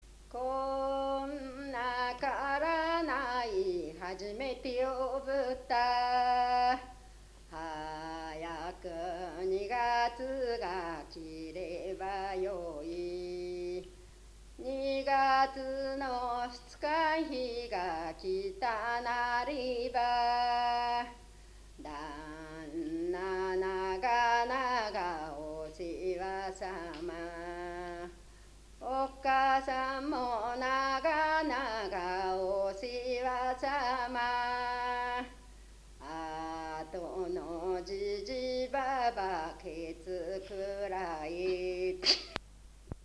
こもりうた 子守歌
64_14_komoriuta.mp3